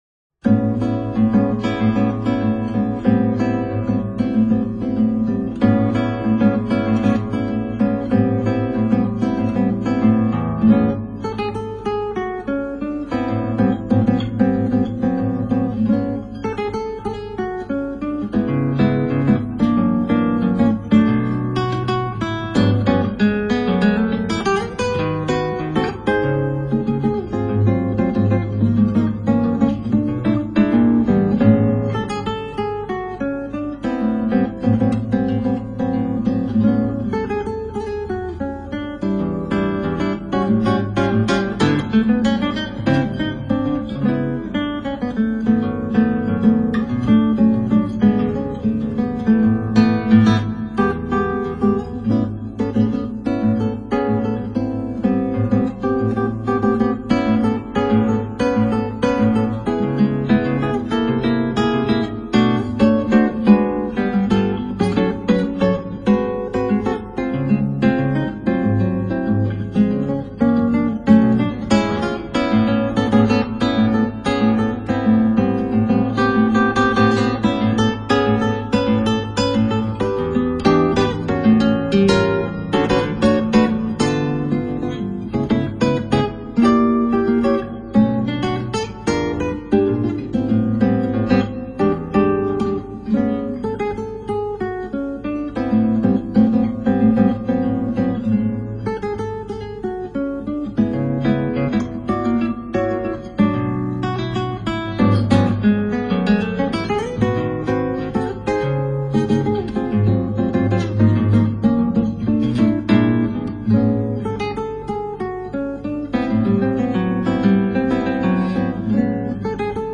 クラシックギター　ストリーミング　コンサートサイト
クラシックもヨイけどやっぱクラギはボサノバしょ！